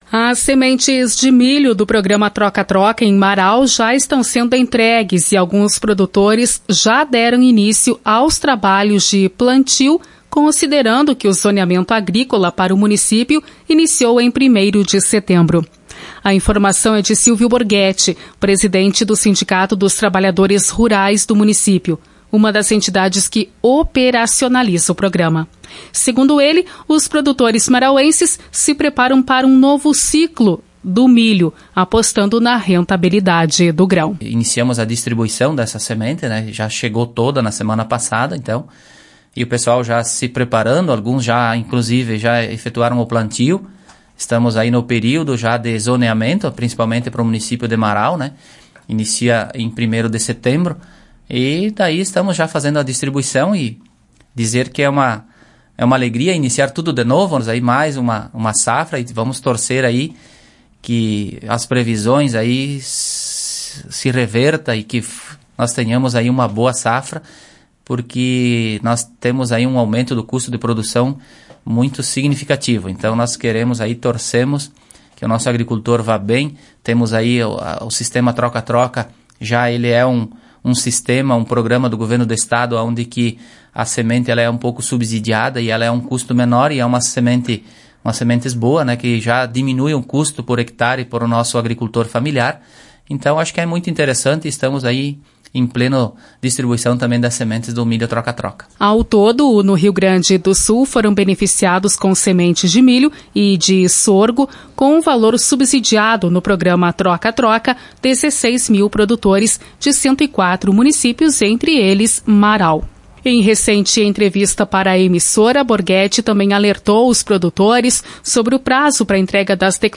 Conteúdo foi ao ar no Programa A Hora do Agricultor da emissora